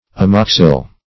Amoxil - definition of Amoxil - synonyms, pronunciation, spelling from Free Dictionary